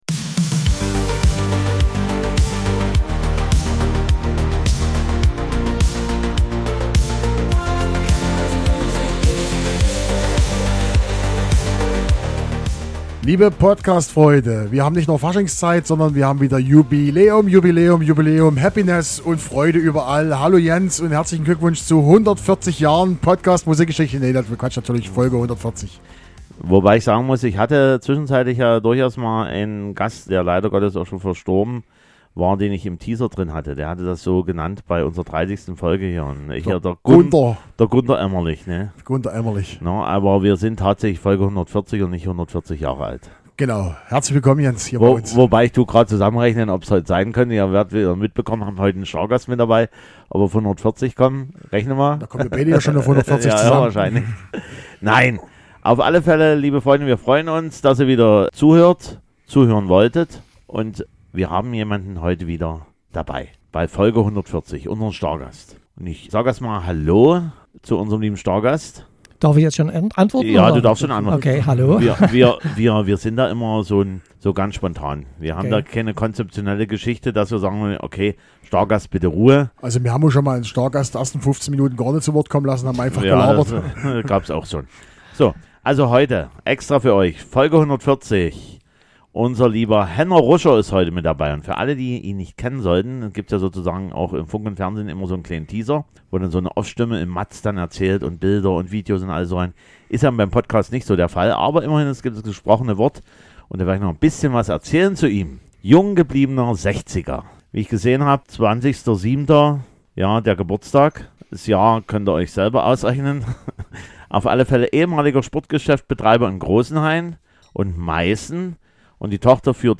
Mit ihm sprechen wir über die Zeit als DJ in der DDR, die Technik und die Schwierigkeiten an die "geile Westmusik" zu kommen. Natürlich gibt es auch Musik, über die wir sprechen. Es gibt nen Schlager und ganz viel Disco-Mugge.